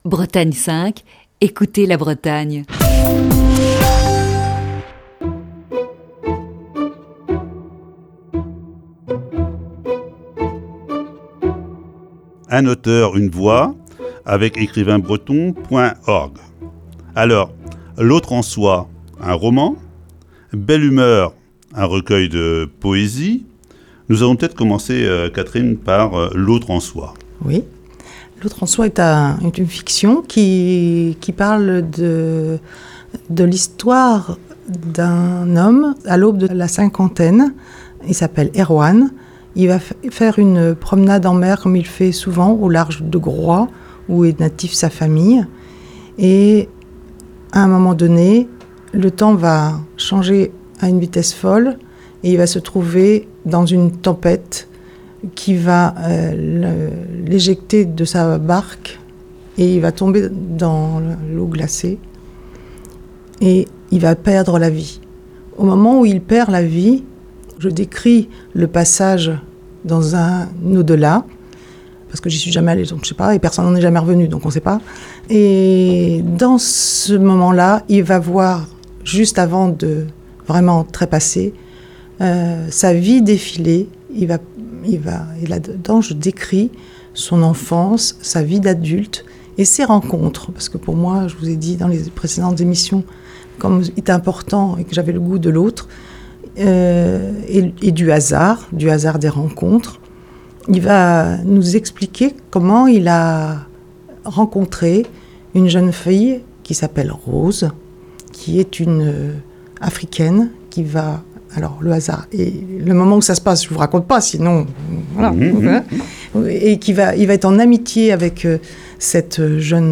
Voici ce vendredi la cinquième et dernière partie de cet entretien.